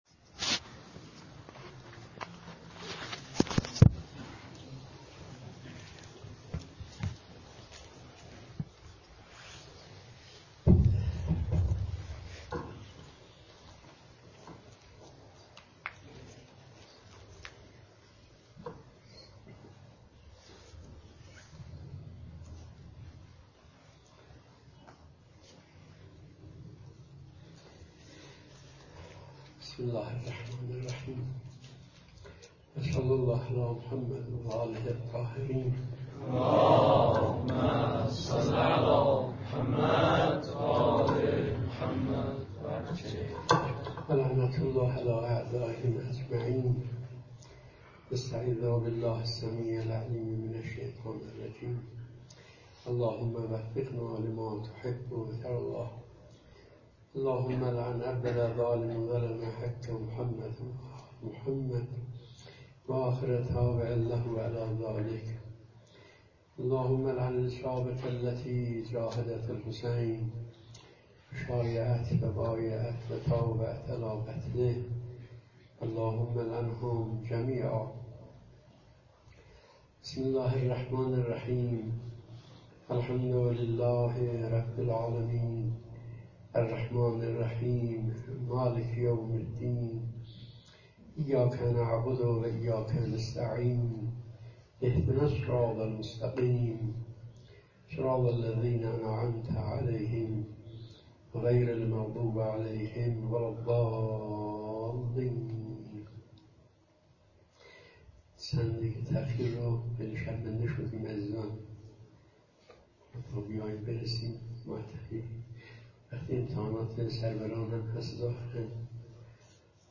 جلسات ماهانه – مسجد امام خمینی (ره) – سالگرد ارتحال آیت الله سعادت پرور (ره)